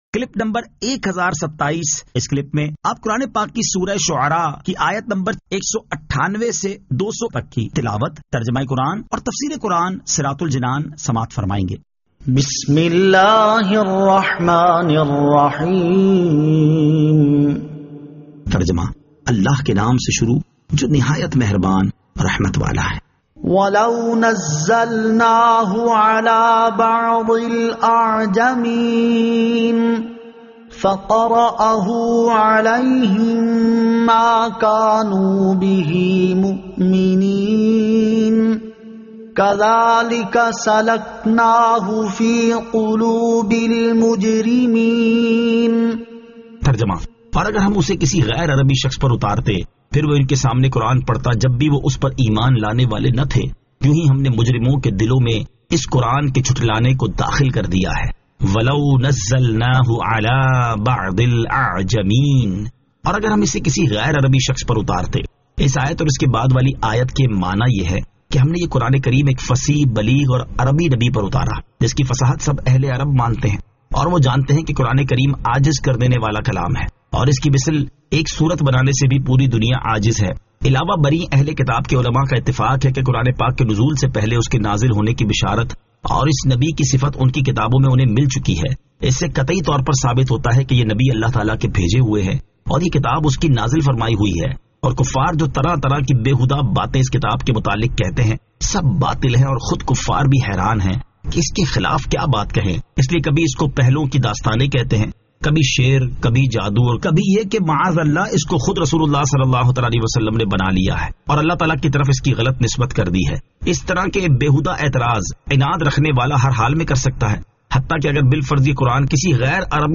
Surah Ash-Shu'ara 198 To 200 Tilawat , Tarjama , Tafseer